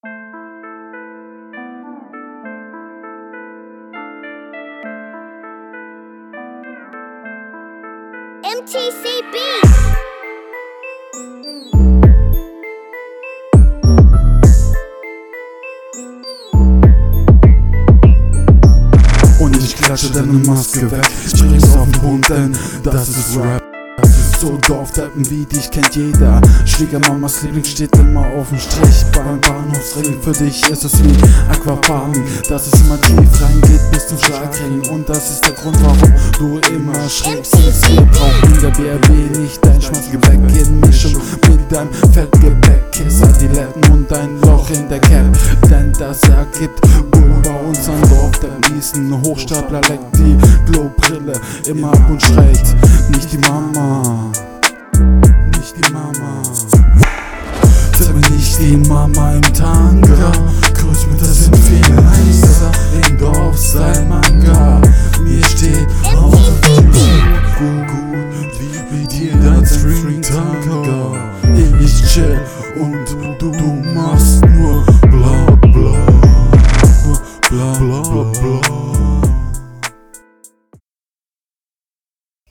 Fresher Beat, Flow und Abmische wie in deinen Runden sonst auch.